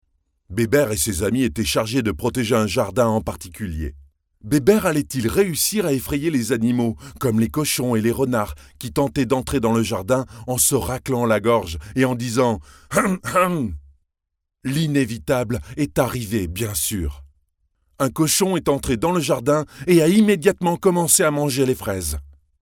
locutor, voice over